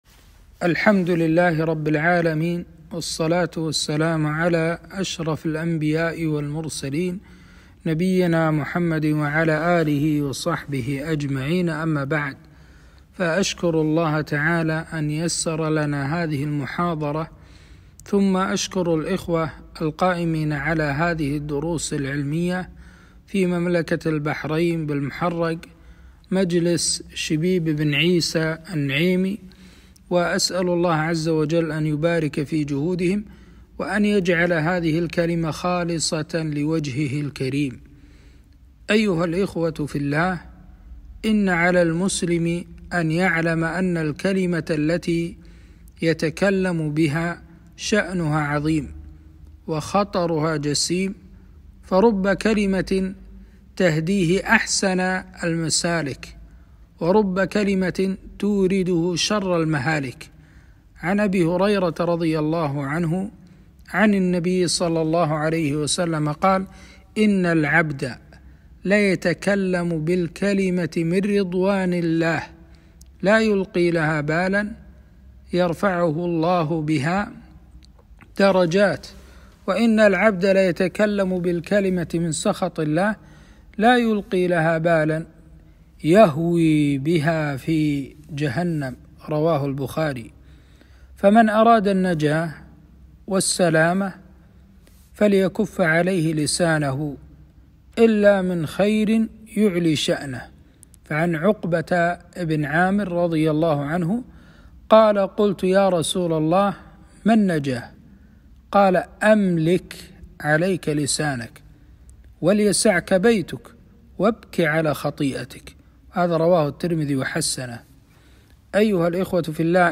محاضرة - خطر الإشاعات في هدم المجتمعات